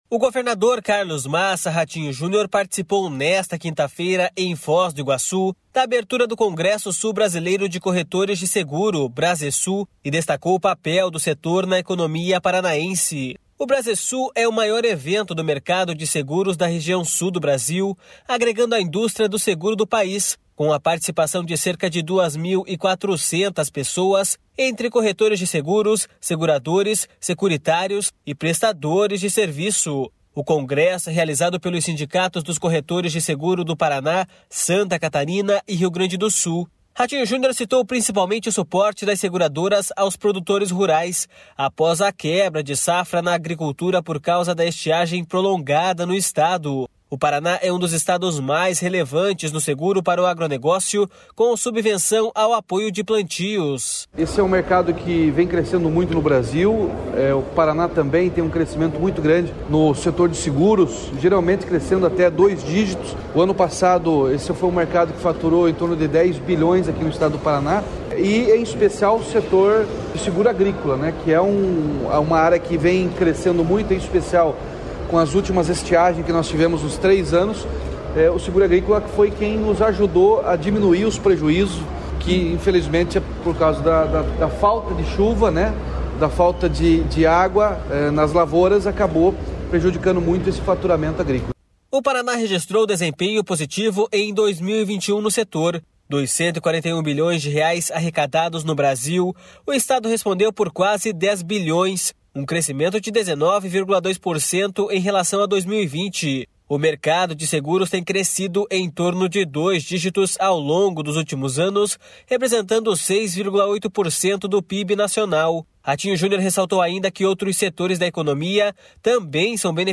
O governador Carlos Massa Ratinho Junior participou nesta quinta-feira em Foz do Iguaçu da abertura do Congresso Sul Brasileiro de Corretores de Seguro, Brasesul, e destacou o papel do setor na economia paranaense.
O Paraná é um dos estados mais relevantes no seguro para o agronegócio, com subvenção ao apoio dos plantios.// SONORA RATINHO JUNIOR.//